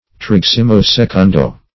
Trigesimo-secundo \Tri*ges"i*mo-se*cun"do\, a. [L. in